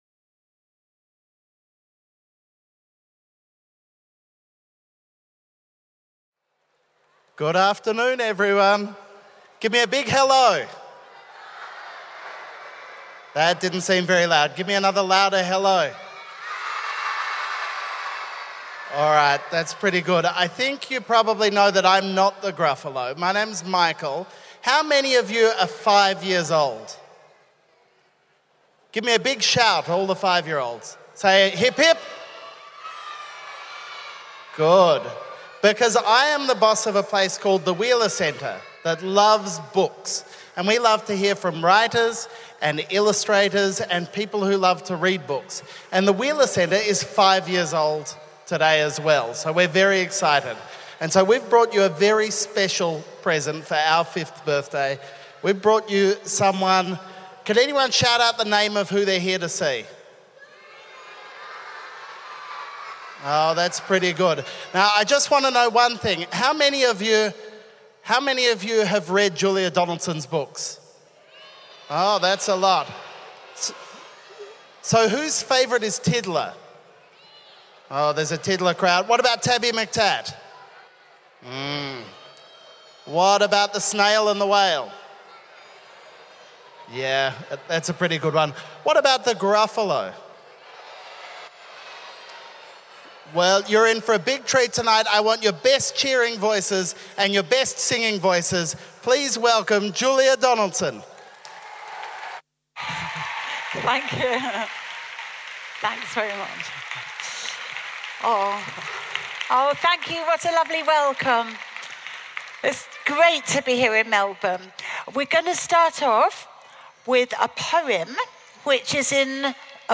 We invited children’s author and entertainer Julia Donaldson, creator of The Gruffalo, to take young readers on a musical and storytelling adventure – along with her many creations, including the Gruffalo.